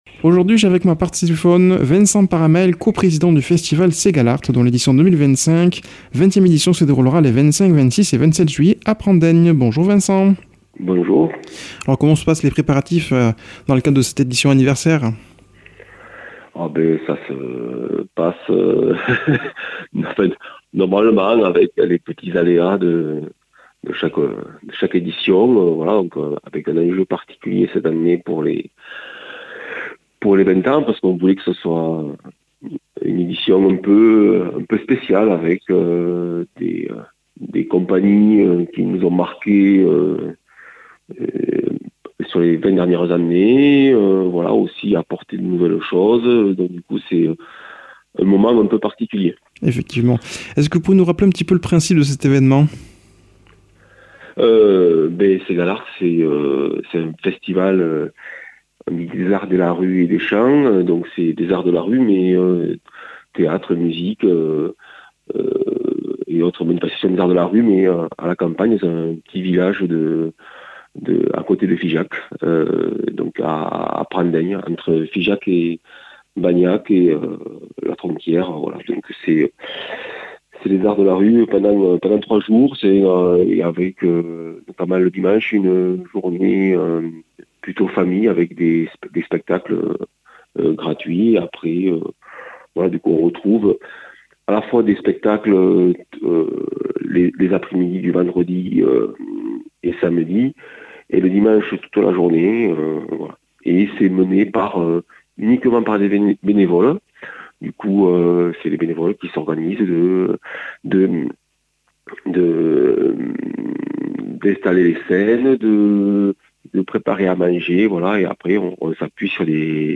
a comme invité par téléphone